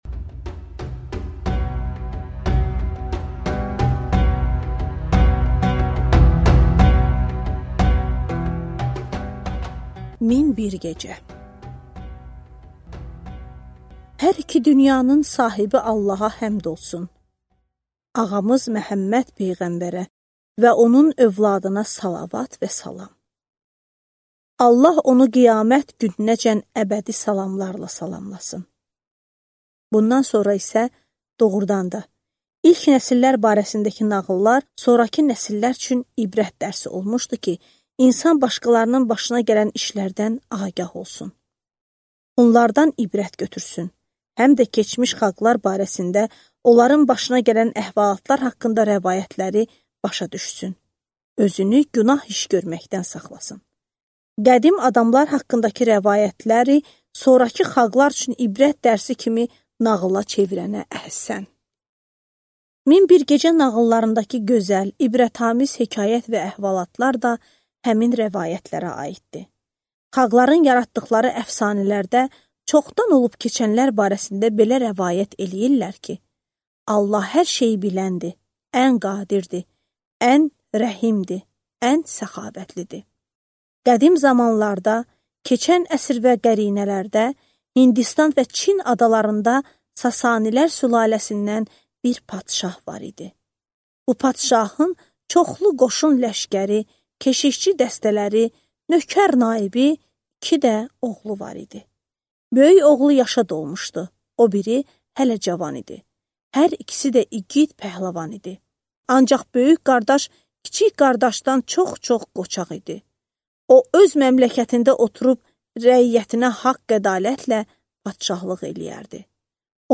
Аудиокнига Min bir gecə 1-ci cild | Библиотека аудиокниг